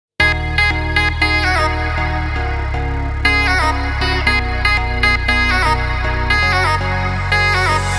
• Качество: 320, Stereo
громкие
Electronic
электронная музыка
без слов
клавишные